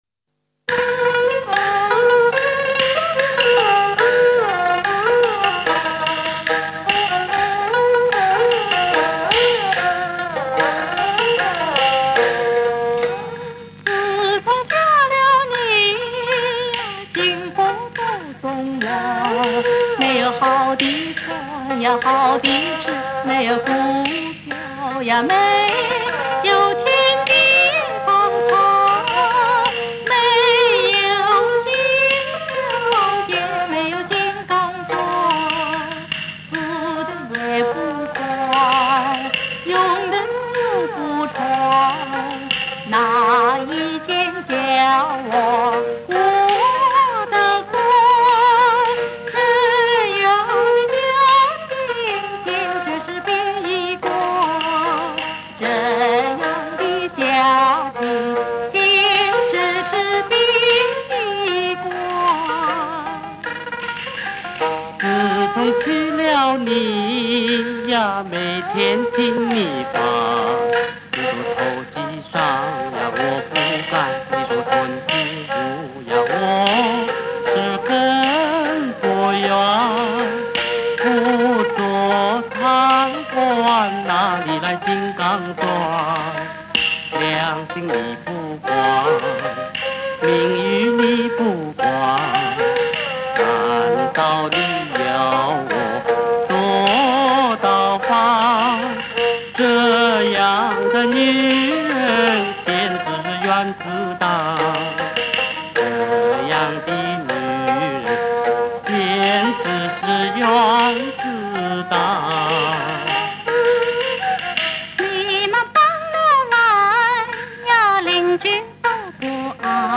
同樣是一人分飾夫妻及二房東三角，也同樣是為了生活標準的牢騷相罵，頗為有趣